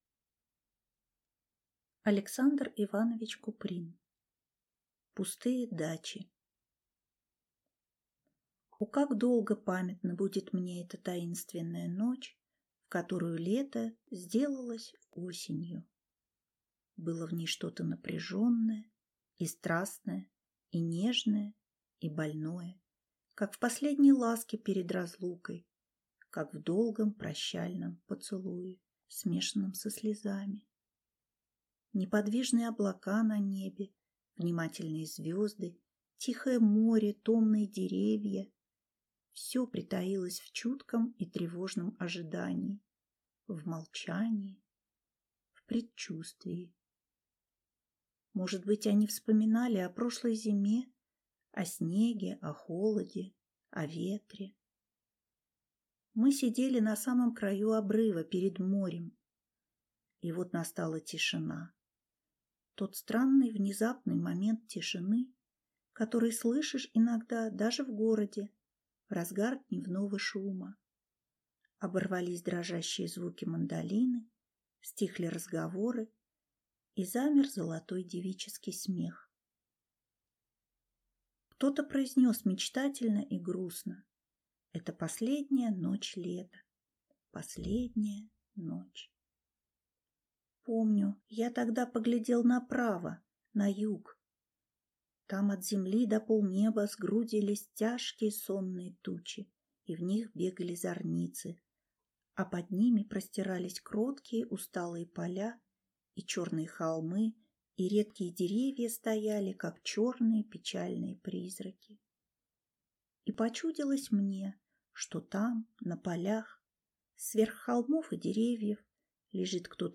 Аудиокнига Пустые дачи | Библиотека аудиокниг